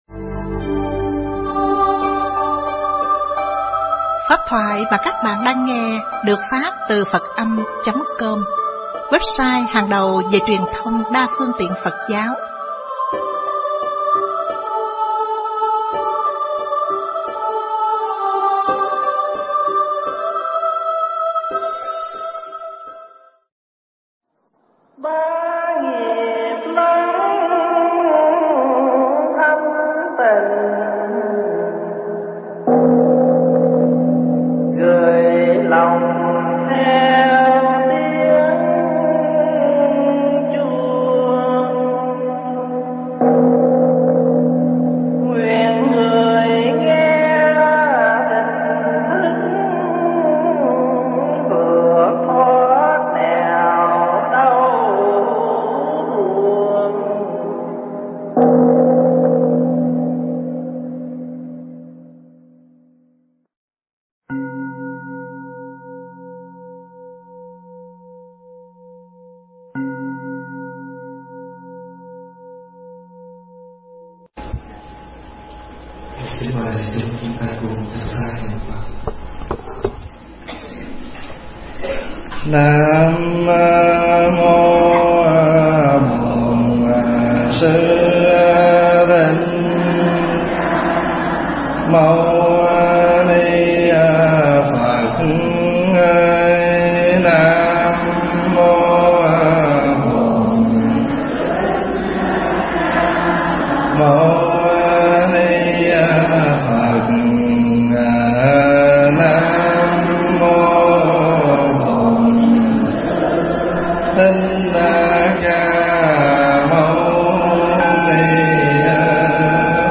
Nghe Mp3 thuyết pháp Tô Đẹp Cuộc Đời